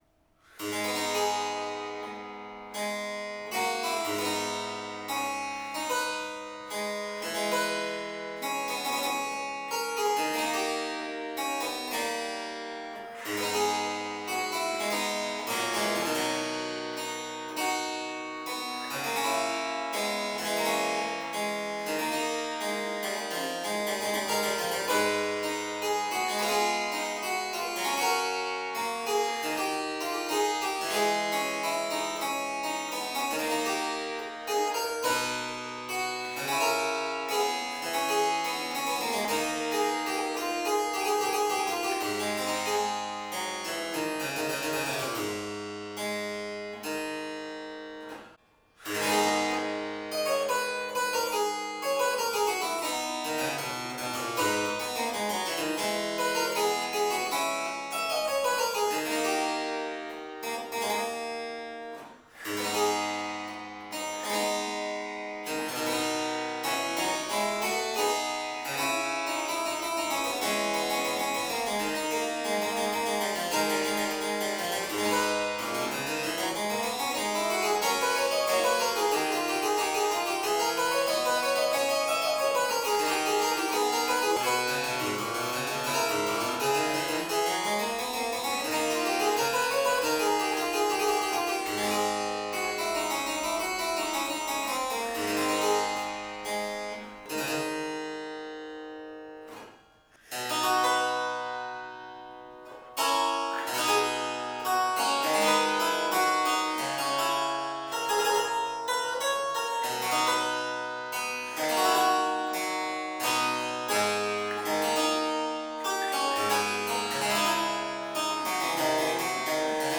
Ruckers Single Manual harpsichord
This instrument is light, compact and resonant, with a strong sound.